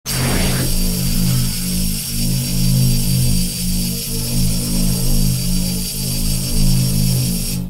Laser Beam fire.mp3